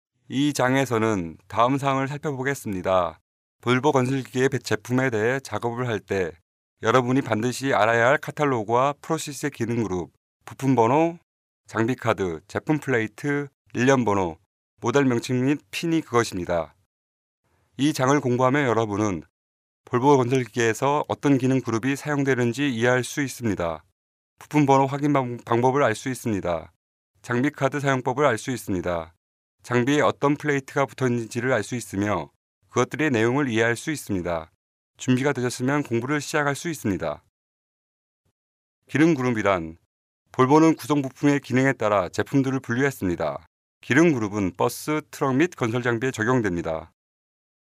Sprecher koreanisch für Werbung, TV, Radio, Industriefilme und Podcasts
Sprechprobe: Werbung (Muttersprache):
Professional korean male voice over artist.